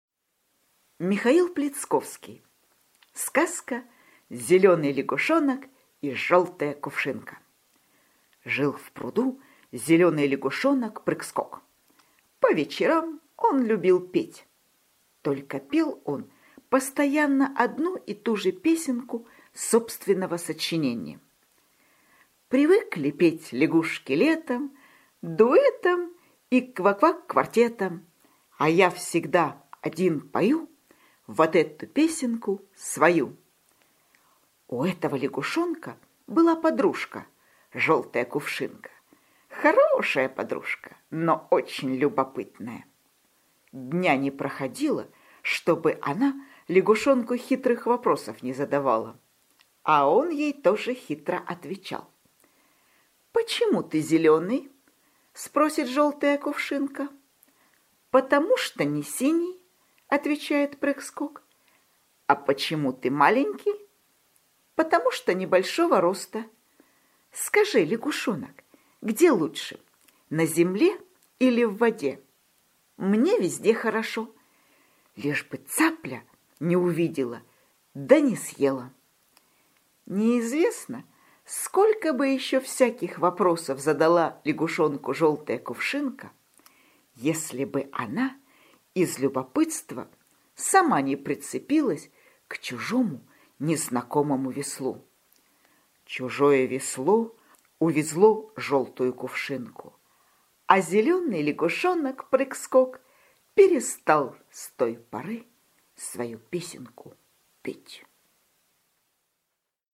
Аудиосказка «Зеленый лягушонок и желтая кувшинка»